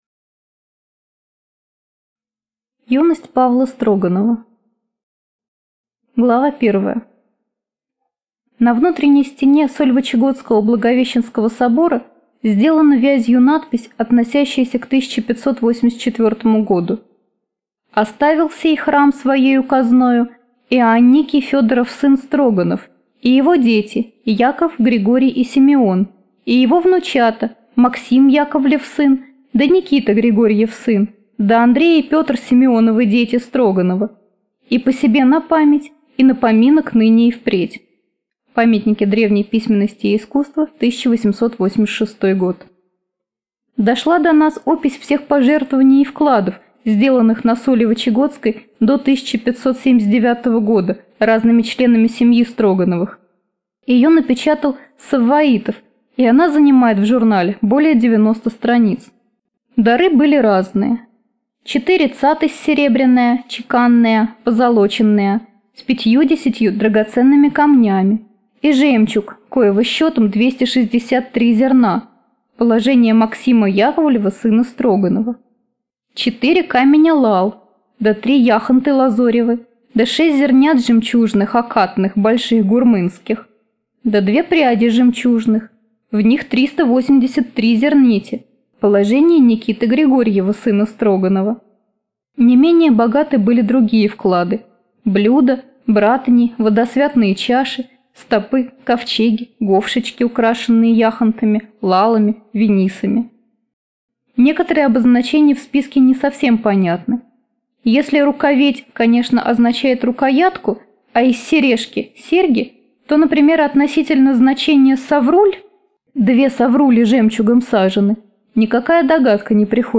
Аудиокнига Жозефина Богарне и ее гадалка. Астролог. Юность Павла Строганова. Коринна в России | Библиотека аудиокниг